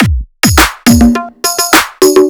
104 BPM Beat Loops Download